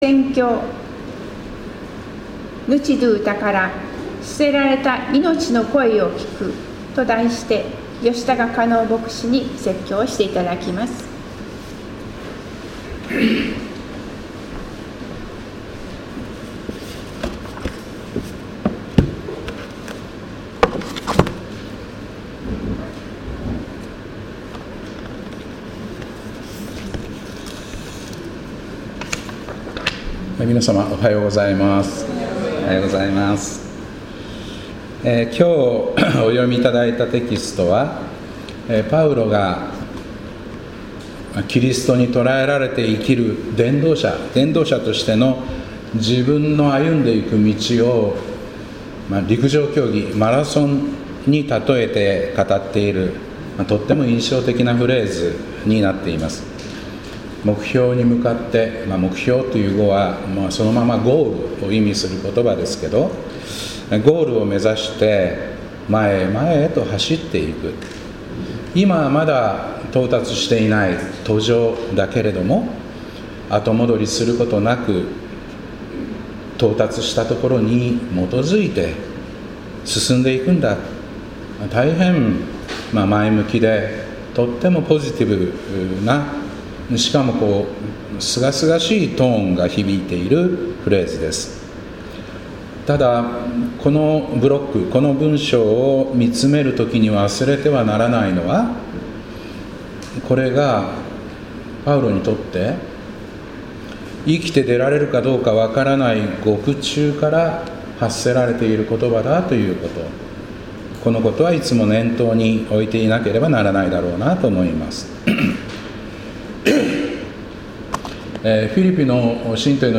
本日の礼拝は、沖縄戦が組織的に終結したとされる「6.23」に因んで「沖縄をおぼえる礼拝」として捧げます。